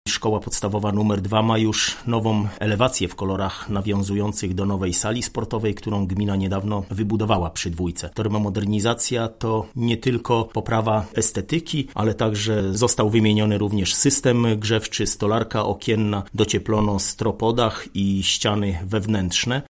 ’- Całość obiektów naszej „dwójki” ma jednolitą gamę kolorystyczną – mówi Mariusz Olejniczak, burmistrz Słubic.